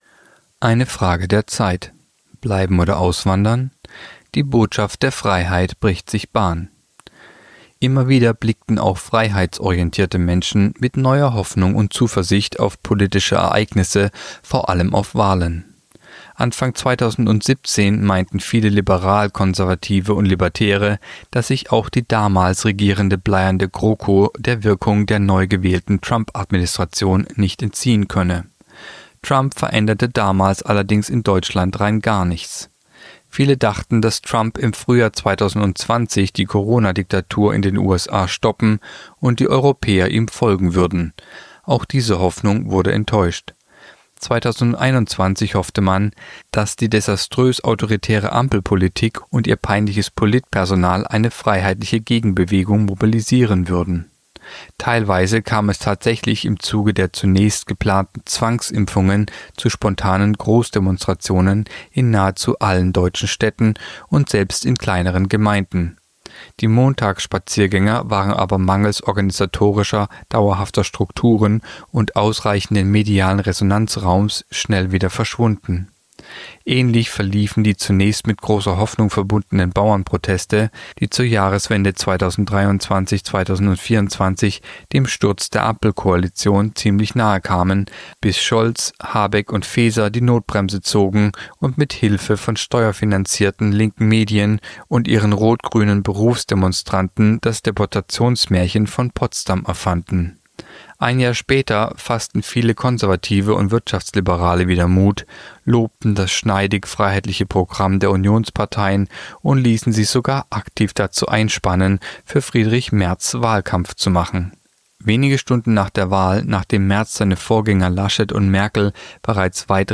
Kolumne der Woche (Radio)Bleiben oder auswandern?